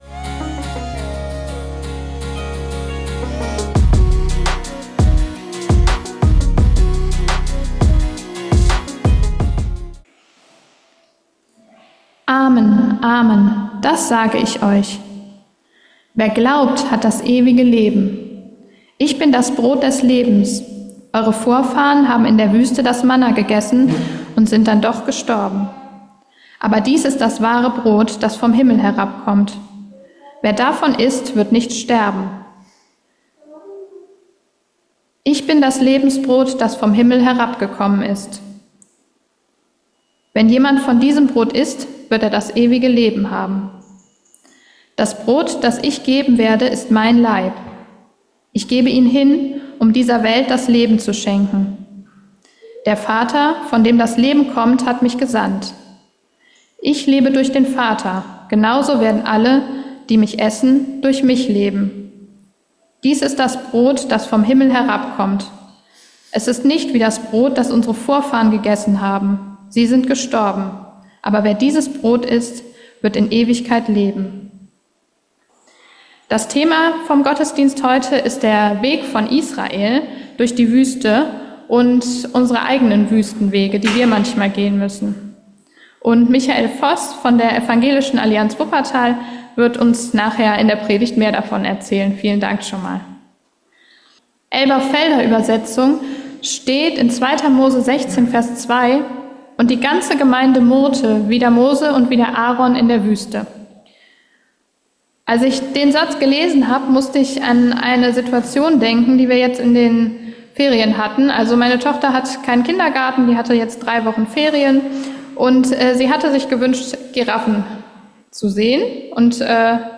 Gott versorgt dich... ~ Geistliche Inputs, Andachten, Predigten Podcast